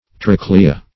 Trochlea \Troch"le*a\, n. [L., a case or sheaf containing one or